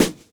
Snares
SWEEPSNR.wav